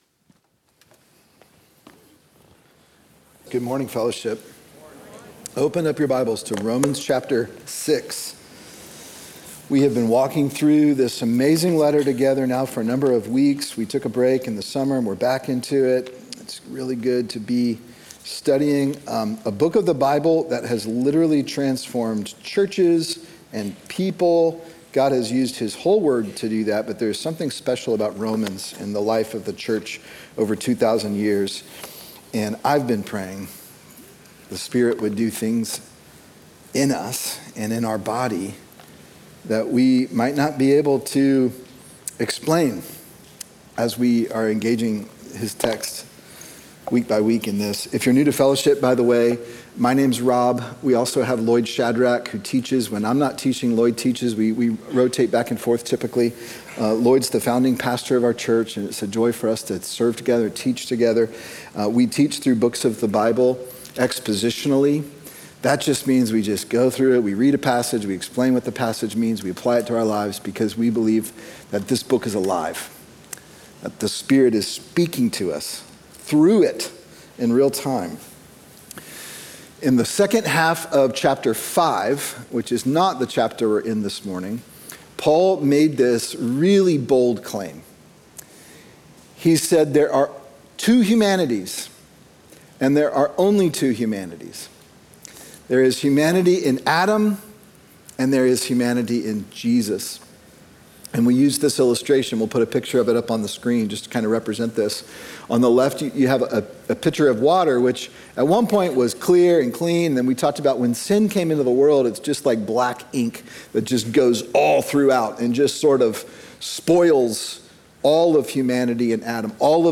Sermon Romans: Righteousness Received